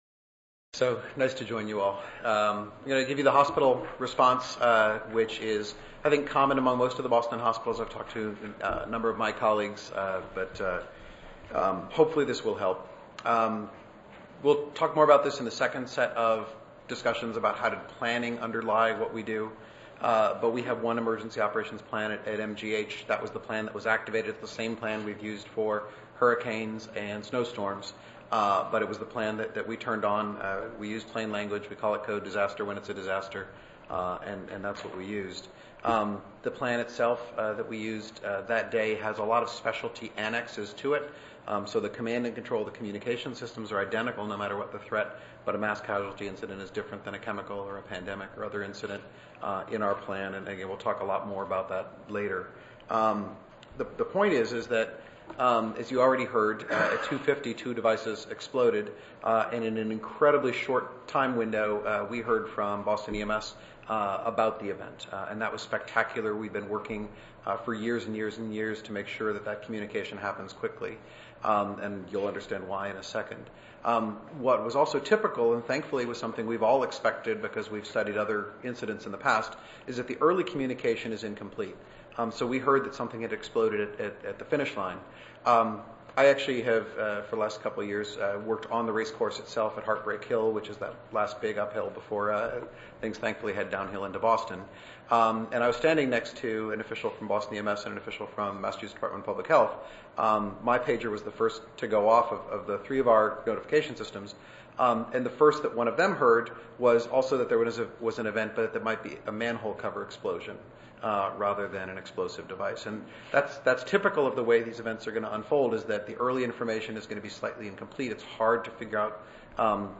141st APHA Annual Meeting and Exposition
Oral